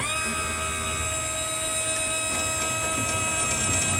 ttr_s_ara_csa_cameraLoop.ogg